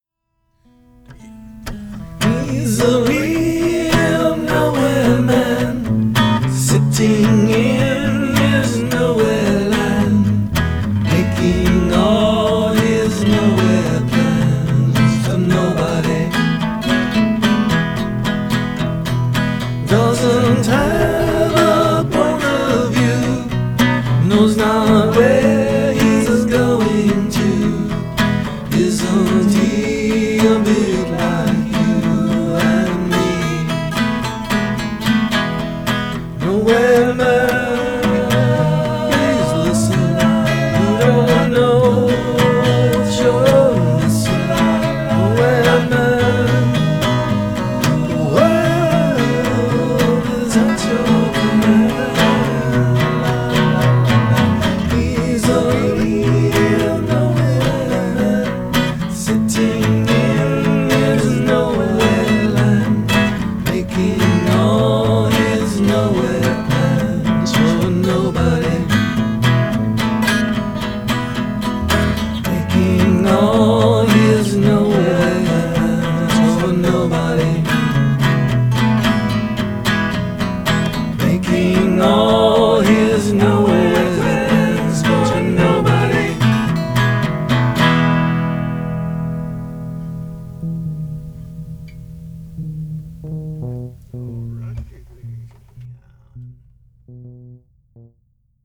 Just love your mix ???? love your vocal.